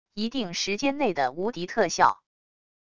一定时间内的无敌特效wav音频